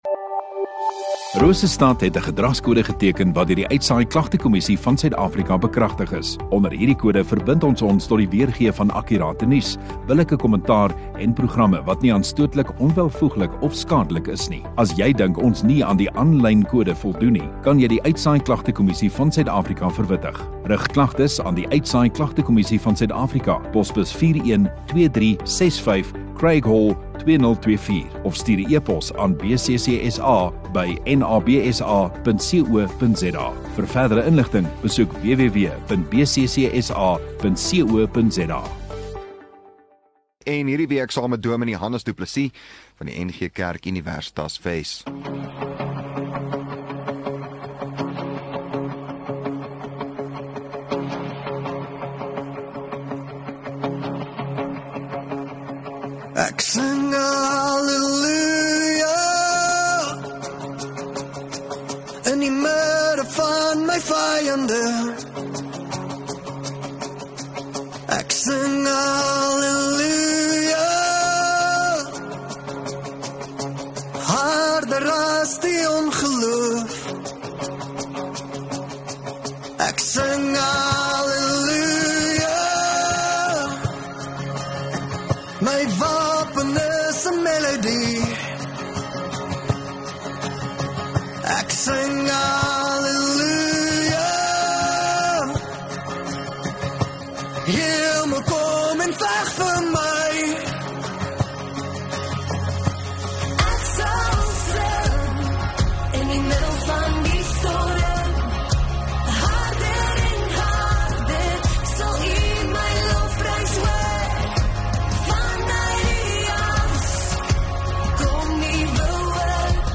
20 May Dinsdag Oggenddiens